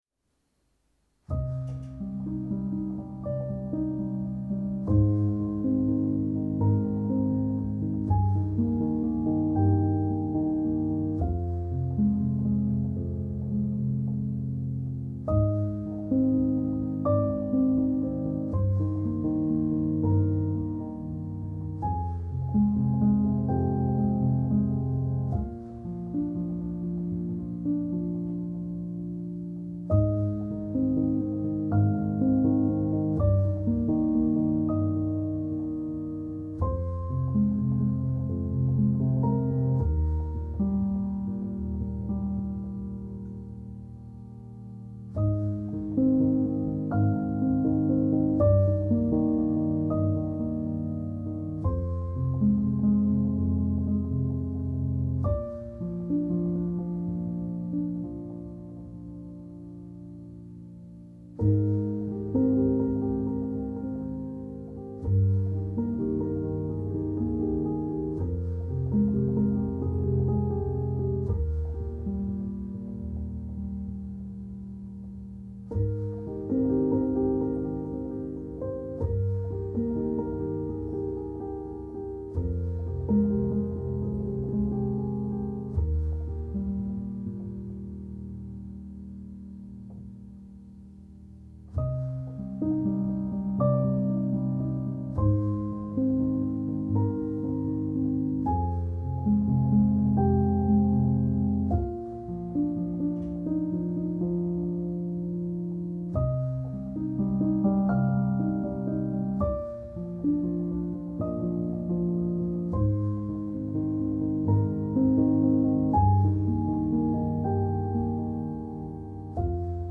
سبک آرامش بخش , پیانو , مدرن کلاسیک , موسیقی بی کلام
پیانو آرامبخش